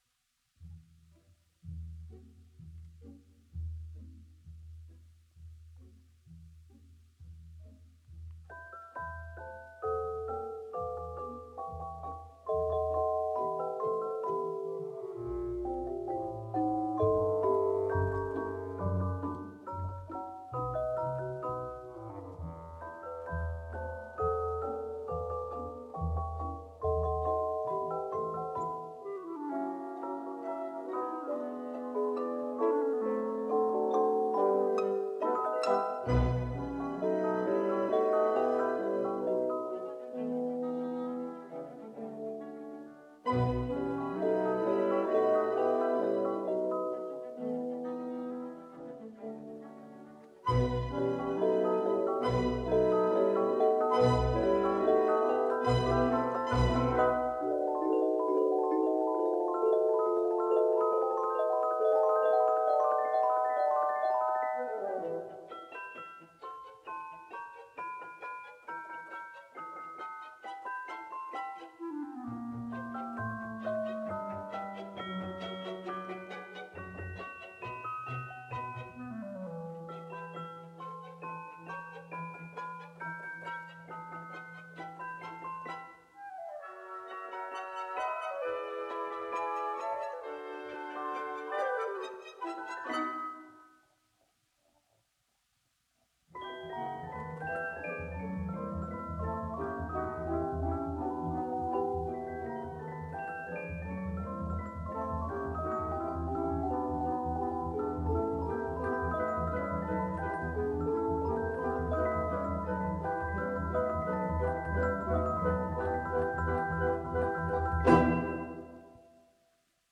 It is scored for 2 flutes, 2 oboes, English horn, 2 clarinets (in A, B-flat), bass clarinet (in B-flat), 2 bassoons + 4 horns (in F), 2 trumpets (in A, B-flat), 3 trombones, tuba + 3 timpani, tambourine, triangle; cymbals + celesta, harp, violins I, violins II, violas, cellos, and double basses. 5
I listened to only the soundtrack of “The Nutcracker”, which was recorded on the 23rd of December 2010 in De Doelen te Rotterdam, played by the “Rotterdams Philharmonisch Orchestra.